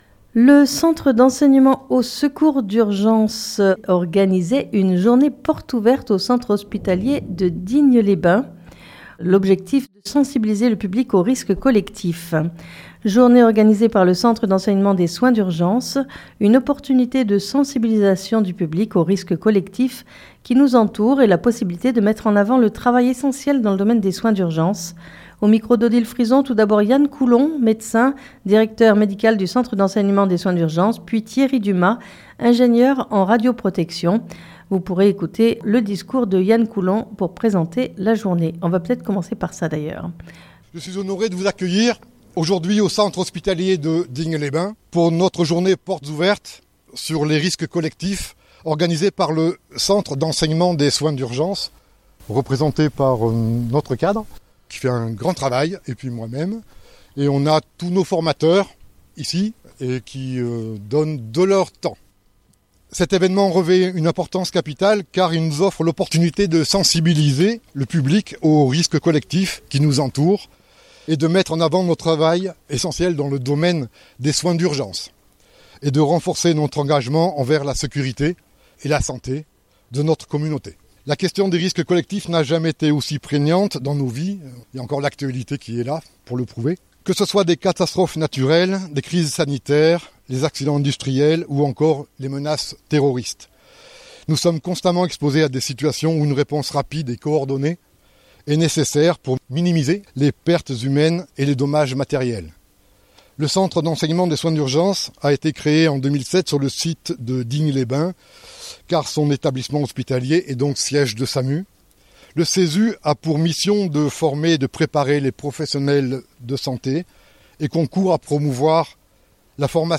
Journée organisée par le centre d'enseignement des soins d'urgence. Une opportunité de sensibilisation du public aux risques collectifs qui nous entourent et la possibilité de mettre en avant le travail essentiel dans le domaine des soins d'urgence.